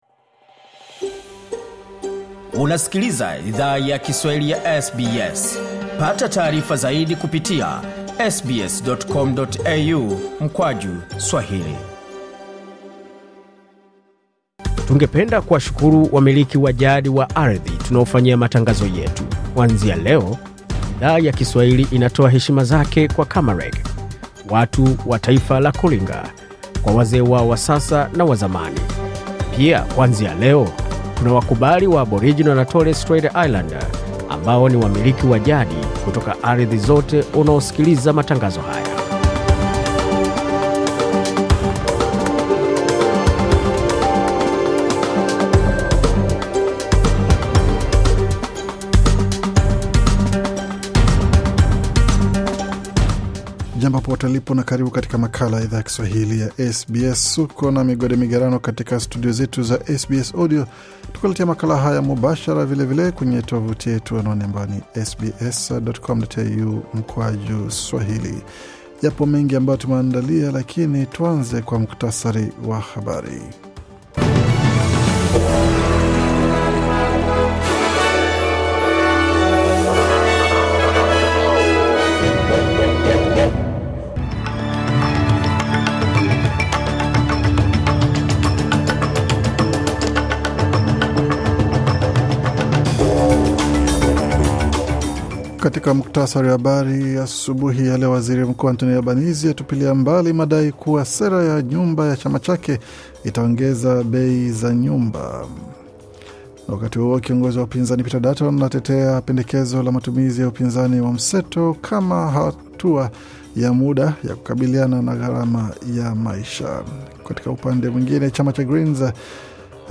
Taarifa ya Habari 15 Aprili 2025